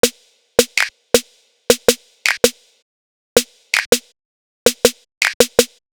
Snare Breakdown.wav